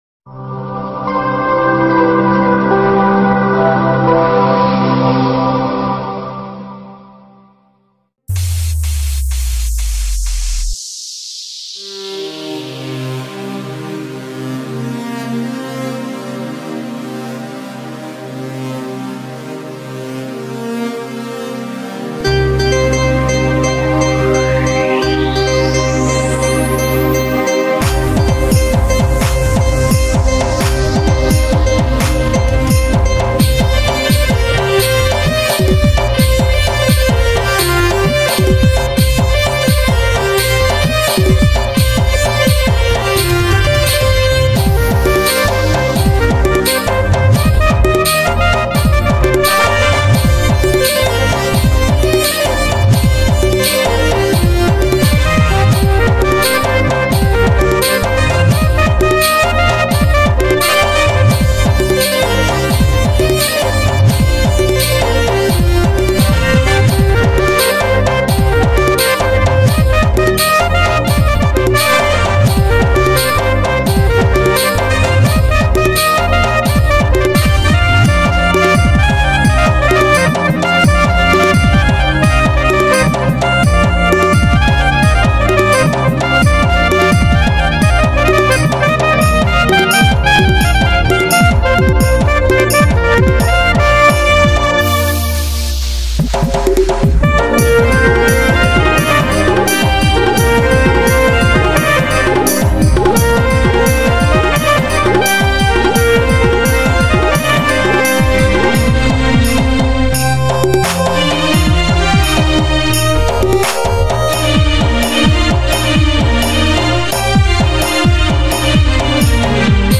Instrumental Music And Rhythm Track